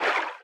Sfx_creature_symbiote_swim_slow_01.ogg